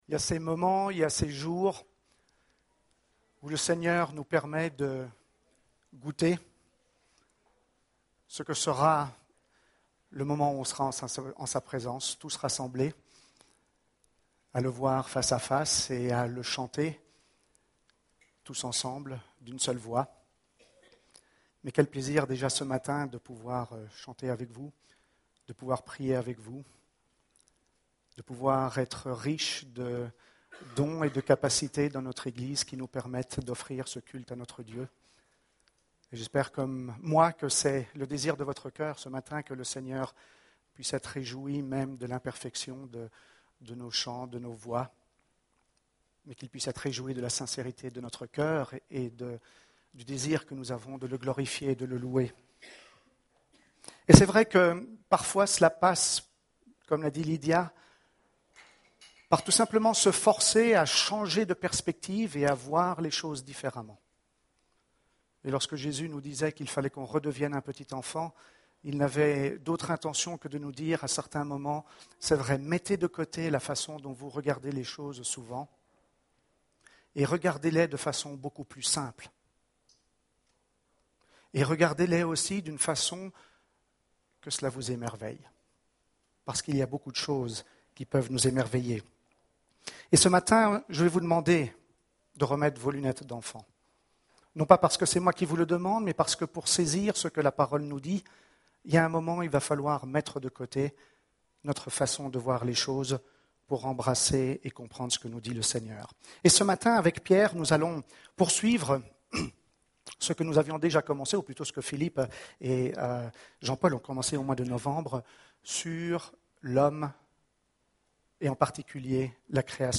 Culte du 3 février